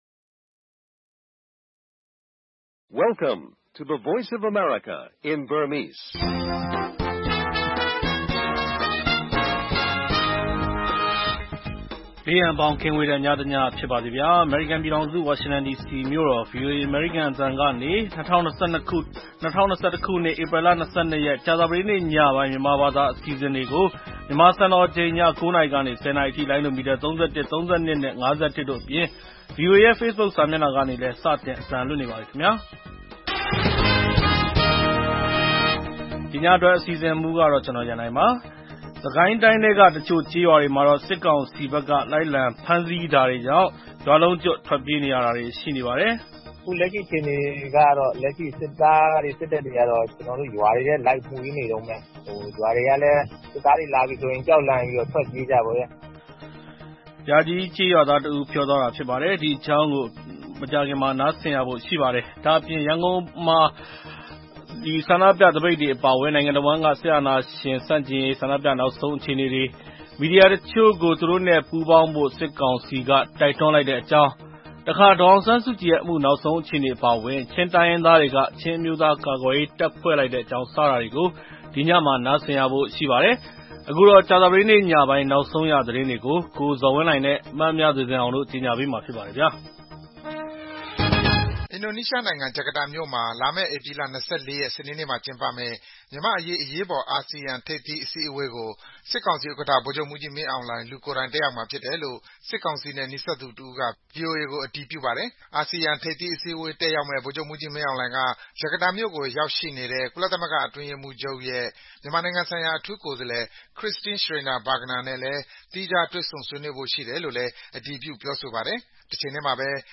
နိုင်ငံတဝန်း ဆန္ဒပြပွဲနဲ့ ဖြိုခွဲမှု နောက်ဆုံးသတင်းတွေနဲ့အတူ မြန်မာ့အရေးသုံးသပ်ချက်၊ ကျန်းမာရေးကဏ္ဍနဲ့ တိုင်းရင်းသတင်းလွှာ အပတ်စဉ်ကဏ္ဍတွေအပါအဝင် ကြာသသပတေးည ၉း၀၀-၁၀း၀၀ နာရီ ရေဒီယိုအစီအစဉ်